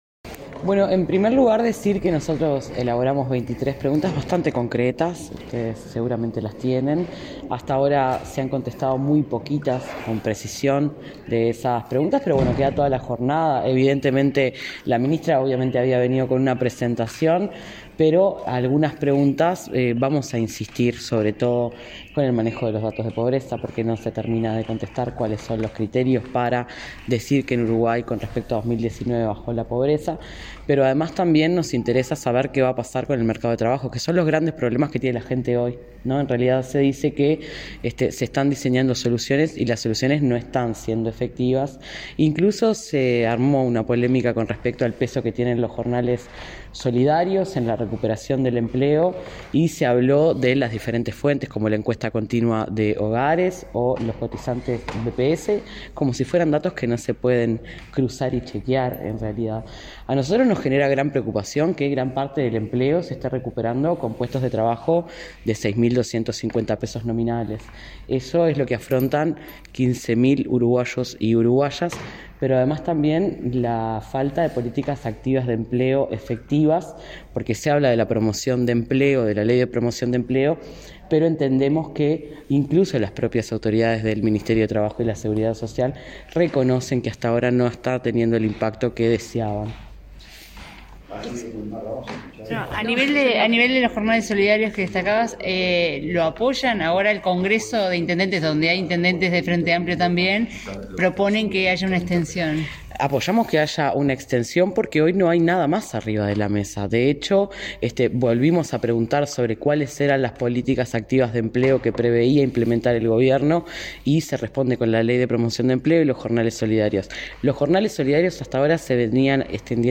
Estas y otras interrogantes son las que plantea la diputada Bettiana Díaz, quien dijo a la prensa esta tarde, que la ministra – al momento – no ha respondido cabalmente a las preguntas realizadas en el Legislativo.
Diaz_sobre_interpelacion-1.mp3